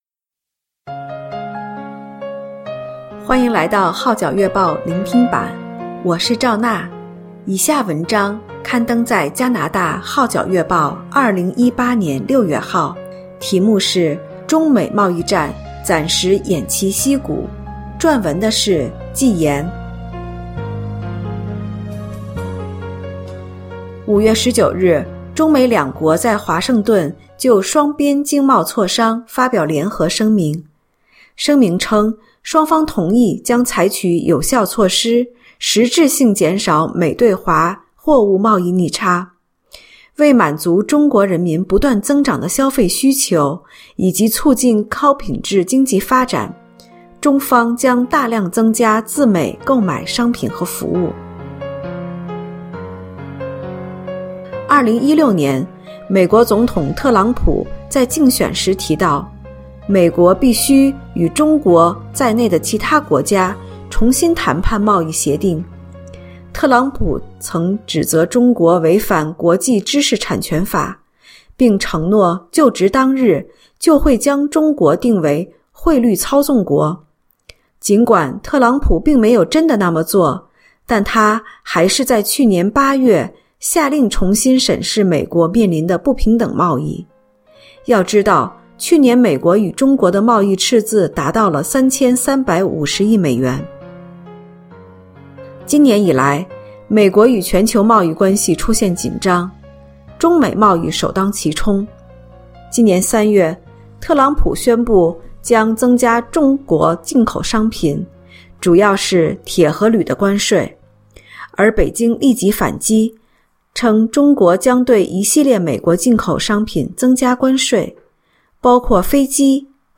聆聽版/Audio中美貿易戰暫時偃旗息鼓